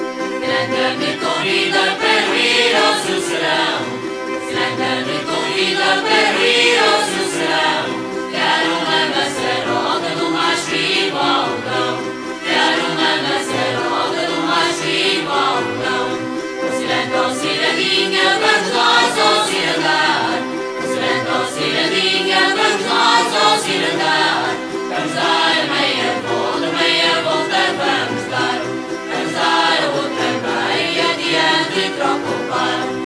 FOLKMUSIC